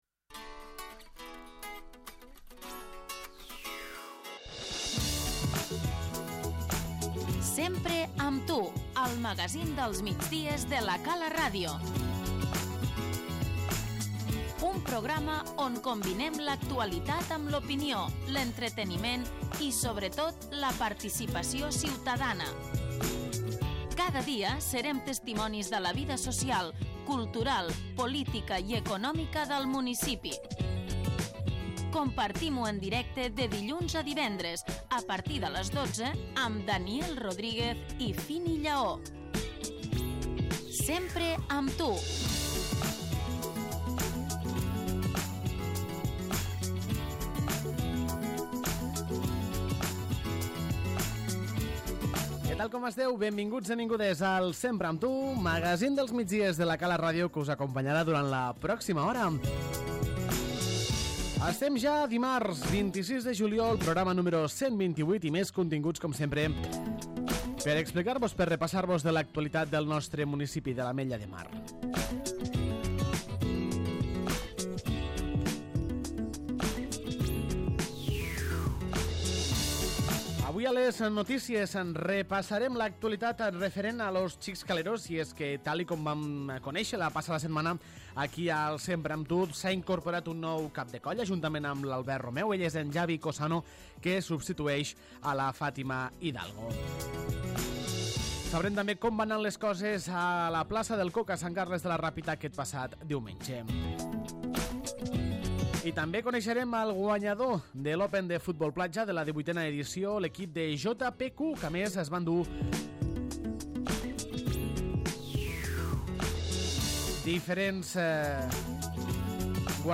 Nova edició del Sempre amb tu, el magazín dels migdies de La Cala Ràdio.